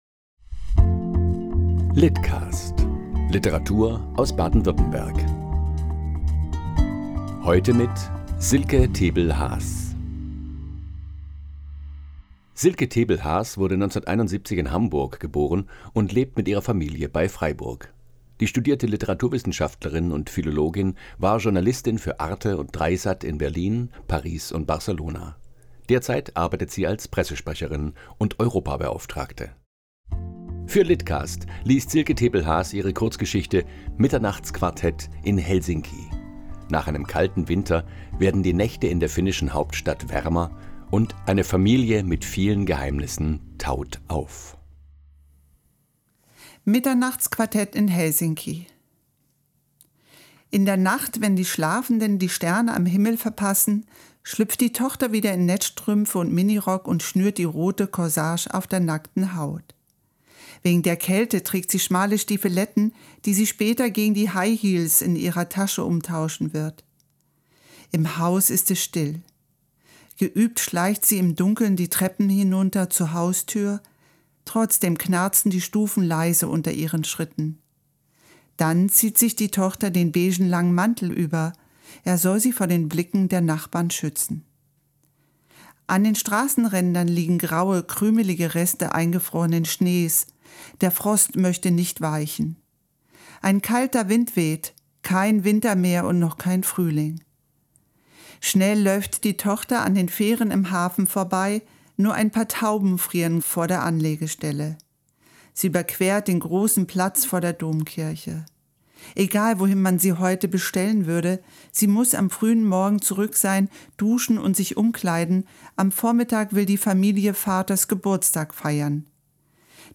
Kurzgeschichte